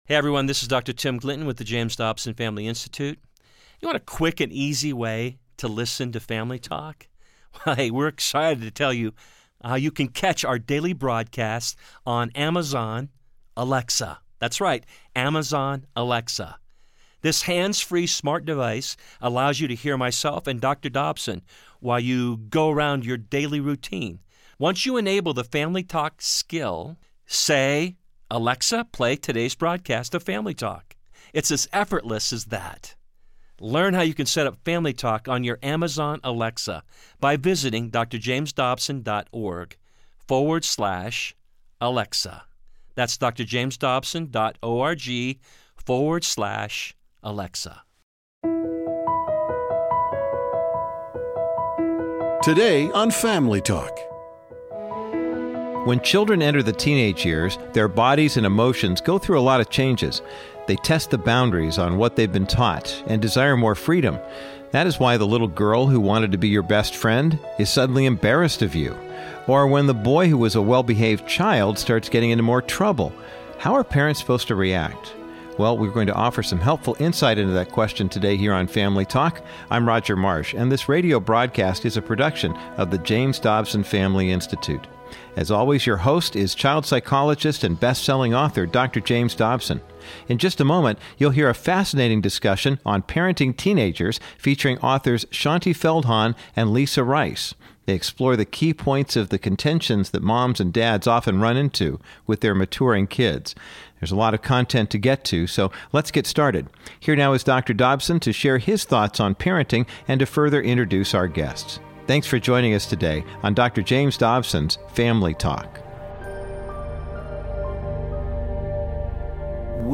Many moms and dads are stunned when their once sweet little kids turn in to unruly teenagers. Dr. Dobson sits down with two expert moms for a discussion on guiding teens through adolescence.